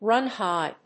アクセントrùn hígh